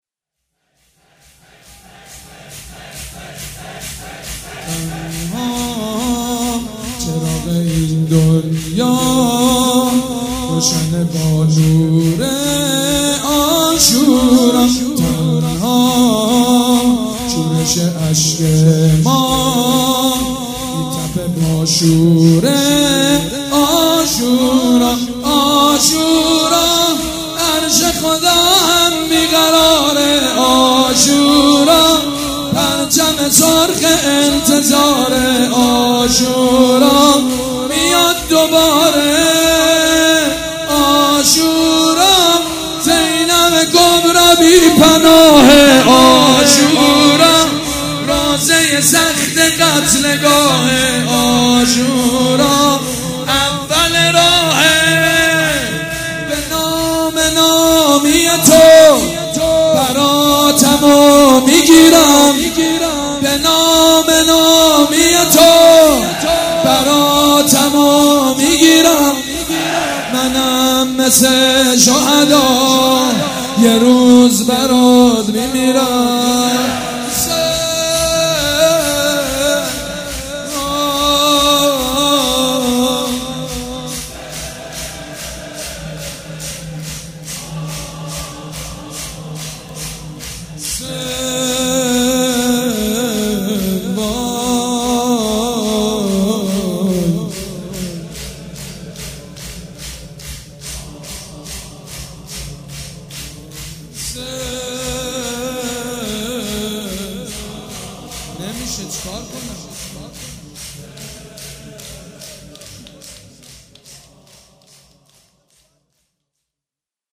شب یازدهم محرم الحرام‌
شور
مداح
حاج سید مجید بنی فاطمه
مراسم عزاداری شب شام غریبان